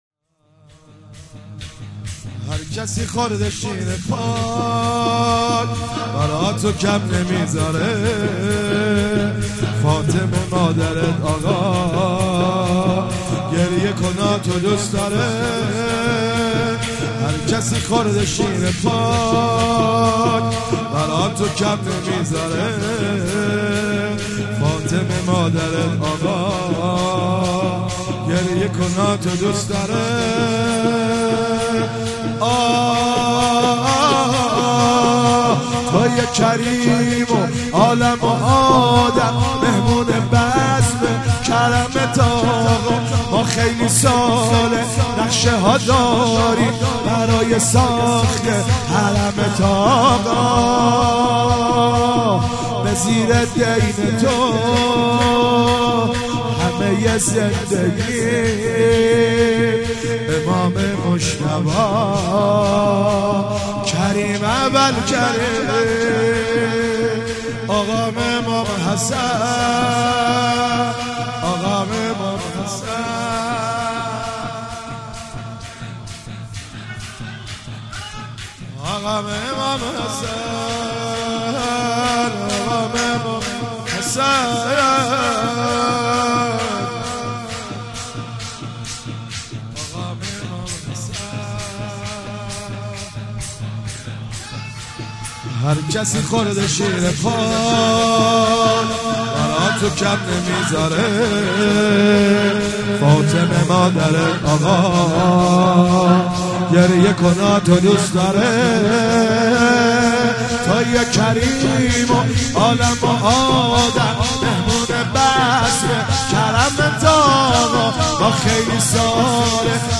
شب 28 صفر96 - شور - هر کسی خورده شیر پاک